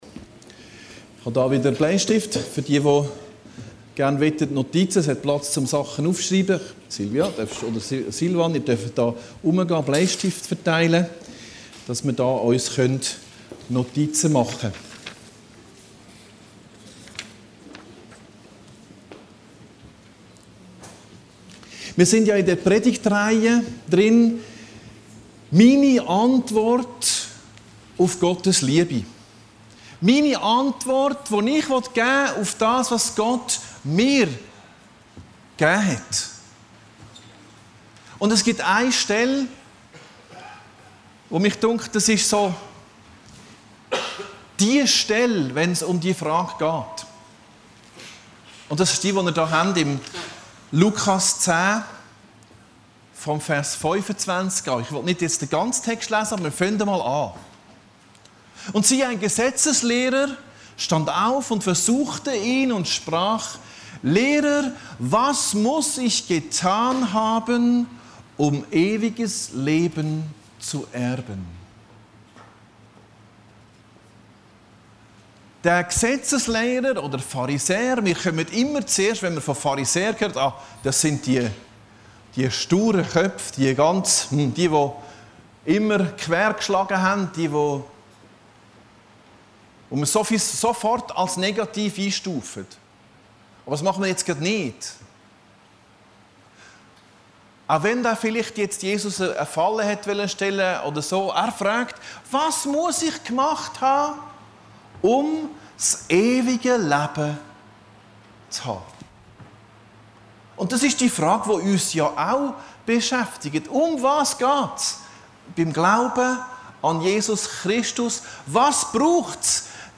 Predigten Heilsarmee Aargau Süd – Den Bedürftigen lieben